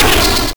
Damaged23.wav